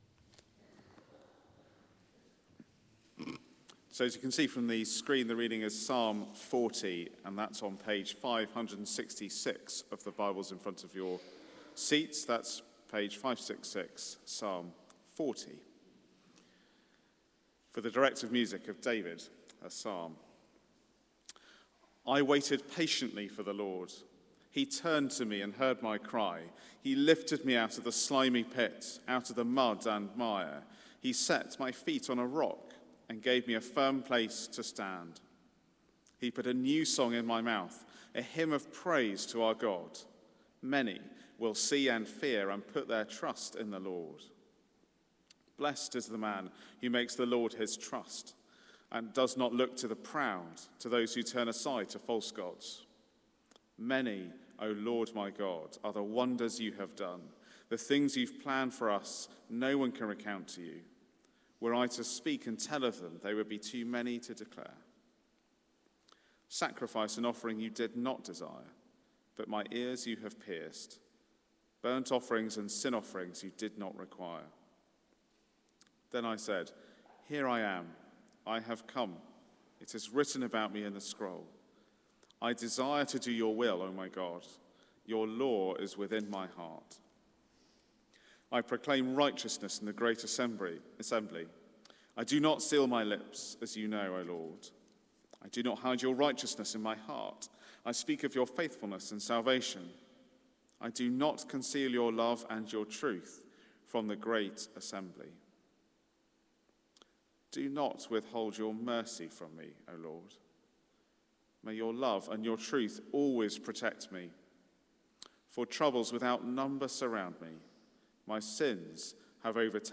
Theme: The King's Rock of Refuge Sermon